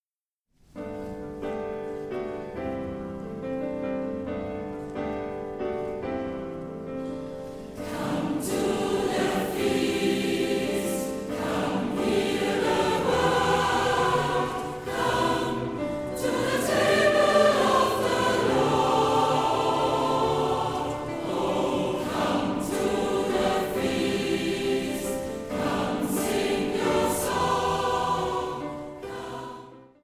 • kurzweilige Zusammenstellung verschiedener Live-Aufnahmen
Chor, Klavier